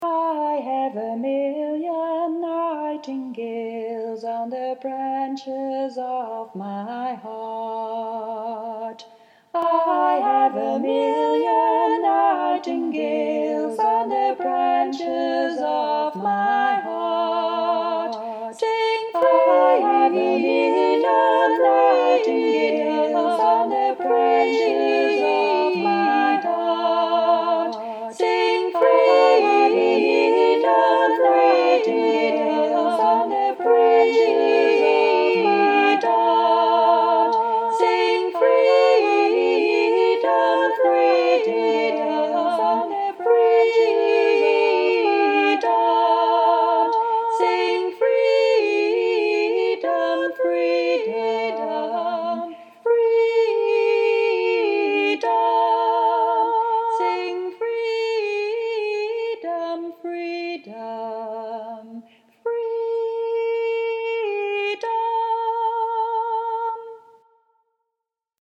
Million-Nightingales-round.mp3